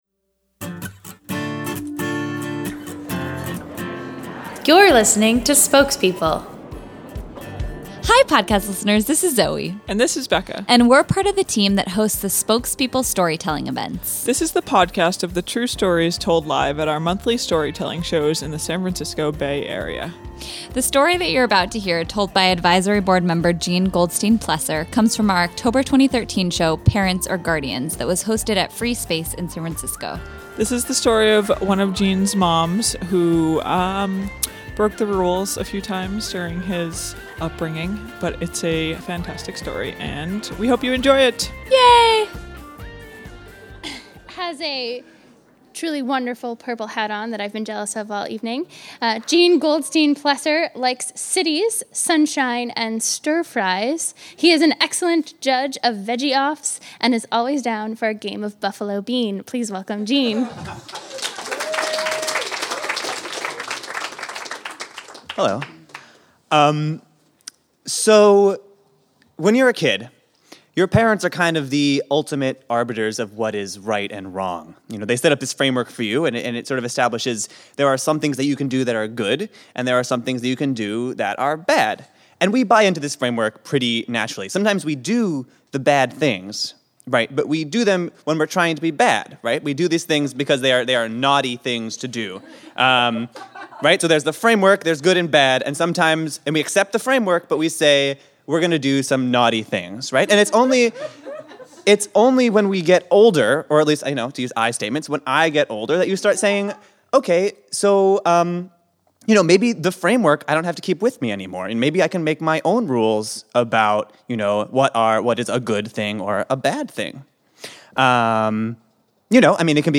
Rules are rules -- except when they're meant to be broken. Today's story of one mom's flexible interpretation of state law (and other rules) comes to us from our October 2013 show, "Parents or Guardians."